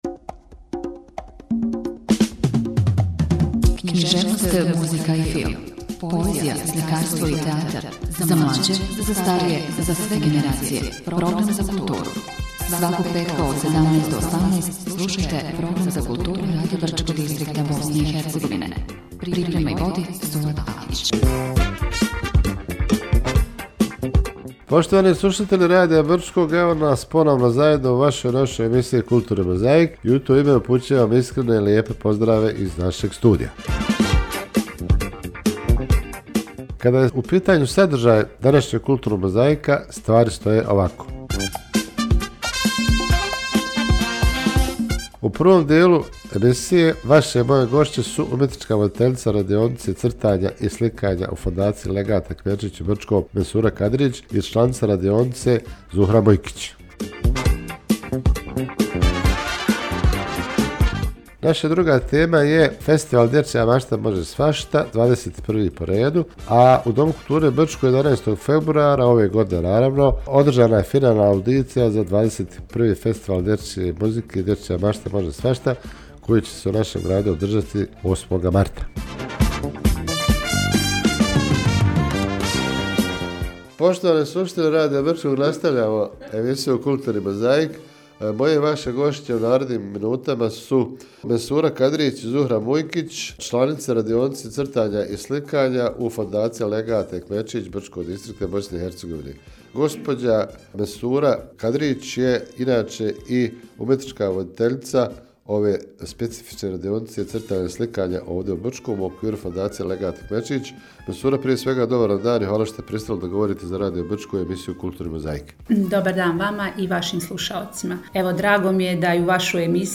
– razgovor